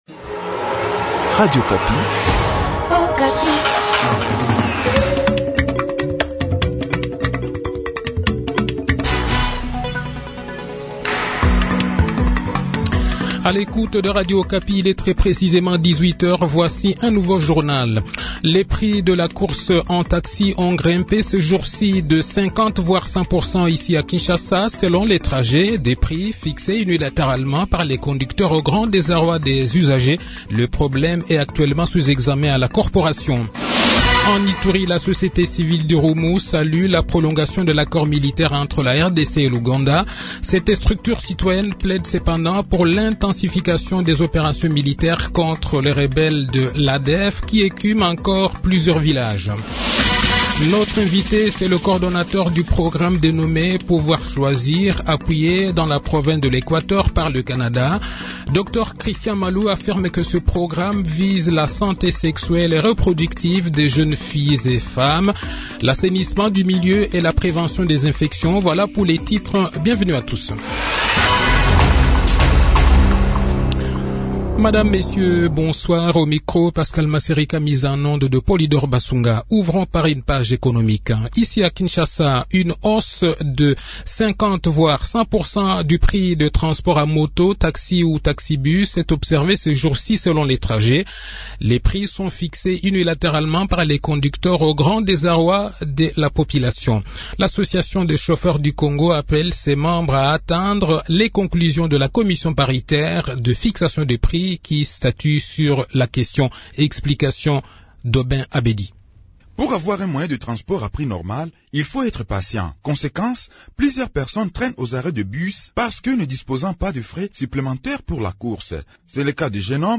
Journal Soir
Le journal de 18 h, 2 juin 2022